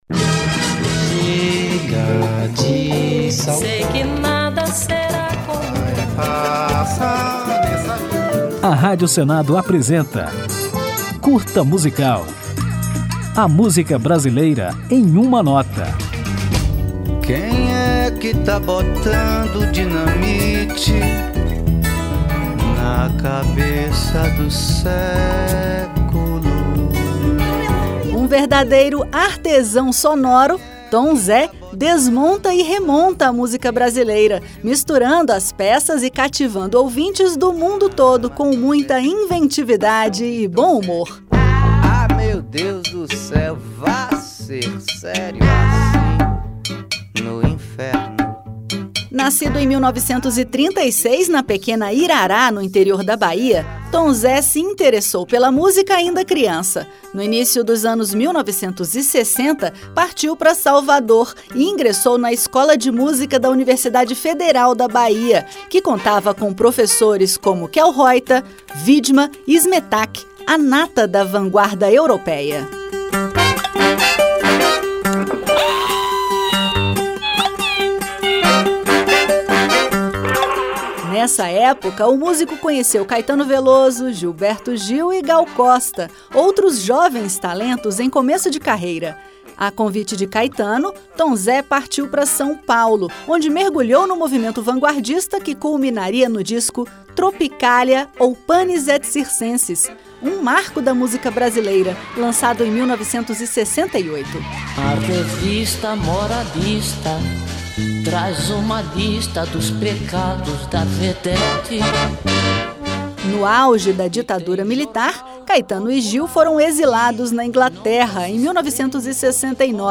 Nesse Curta Musical, você vai conhecer um pouco da história desse artesão sonoro de grande inventividade e bom humor e ainda ouvir Tom Zé na música Tô, lançada em 1976, no disco Estudando o Samba.